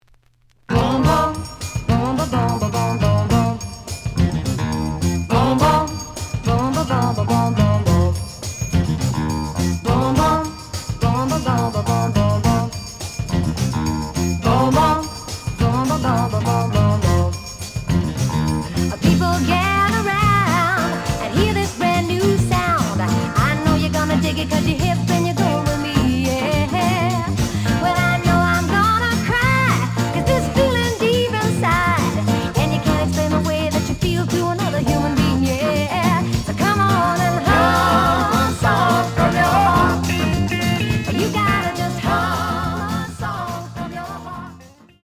The audio sample is recorded from the actual item.
●Format: 7 inch
●Genre: Rock / Pop